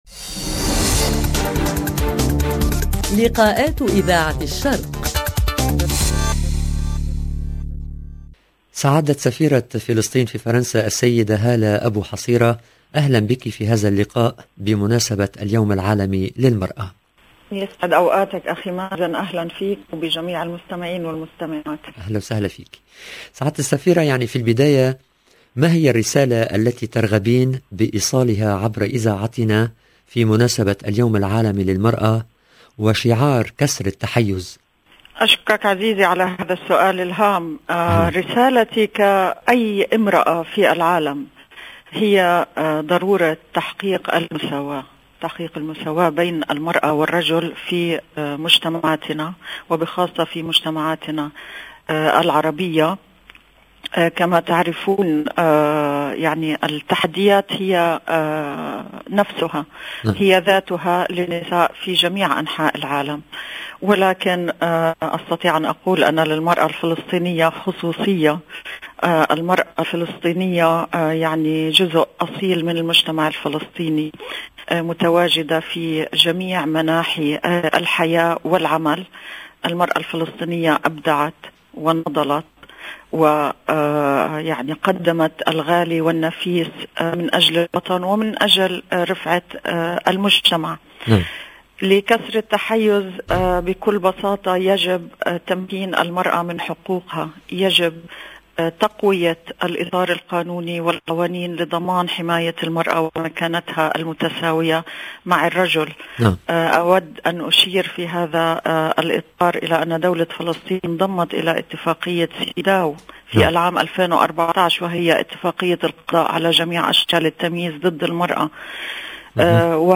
8 mars 8 mars 2022 - 7 min 25 sec Spécial 8 mars avec Hala Abou Hasira LB Likaat, mardi 8 mars 2022 لقاء مع سفيرة فلسطين في فرنسا السيدة هالة ابو حصيرة تتحدث لاذاعة الشرق بمناسبة اليوم العالمي للمرأة 0:00 7 min 25 sec